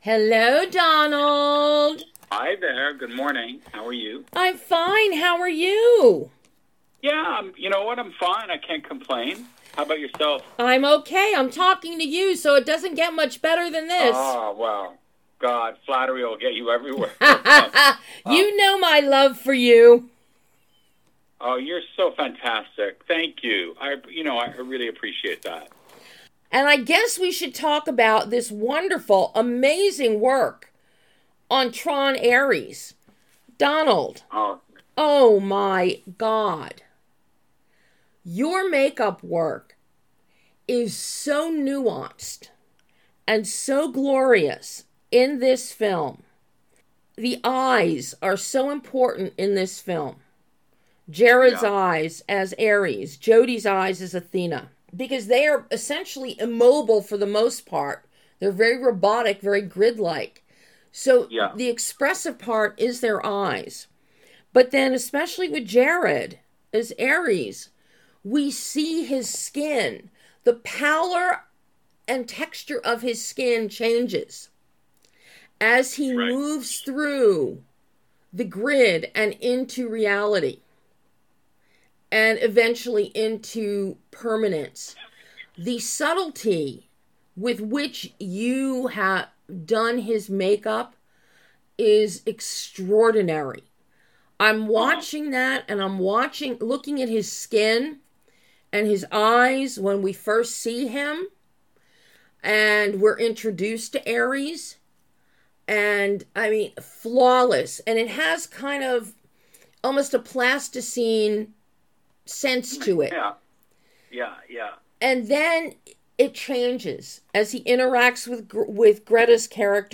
and precision in TRON: ARES - Exclusive Interview